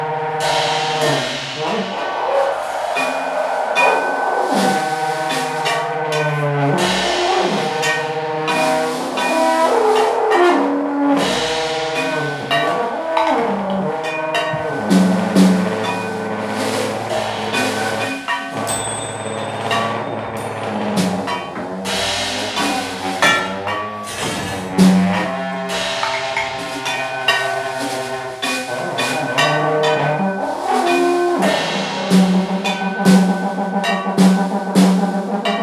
percussionist